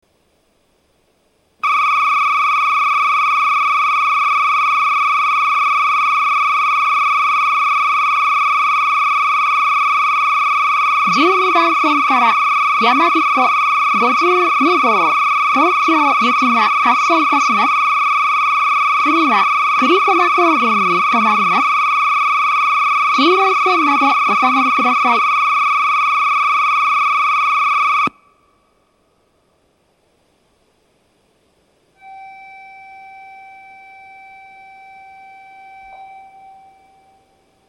発車ベル
非常にけたたましい音色の発車ベルが流れていました。